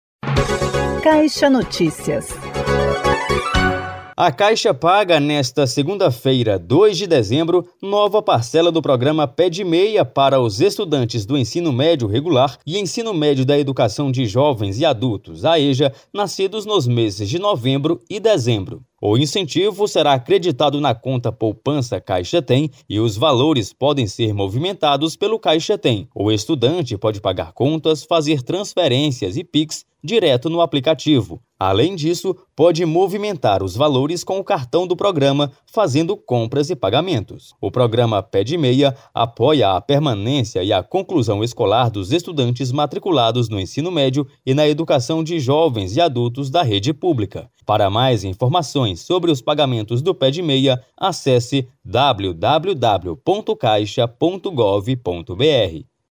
Ministra da Cultura fala sobre importância da CAIXA Cultural Salvador, que completa 25 anos